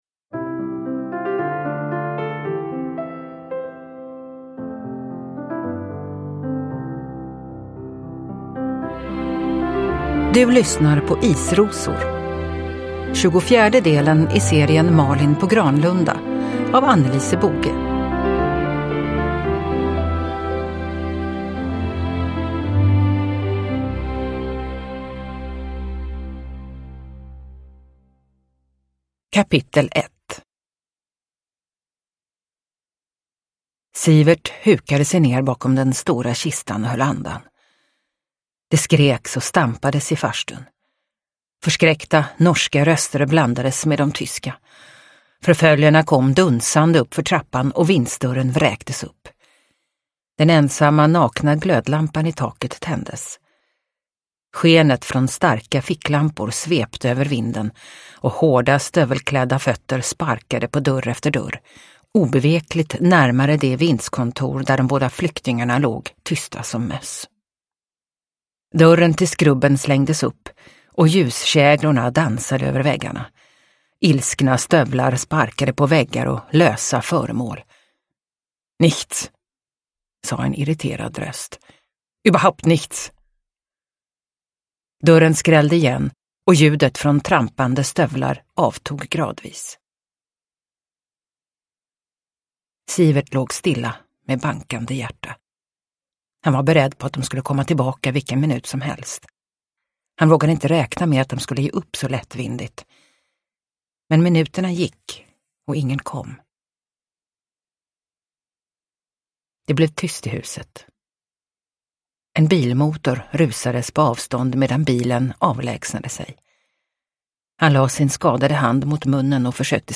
Isrosor – Ljudbok – Laddas ner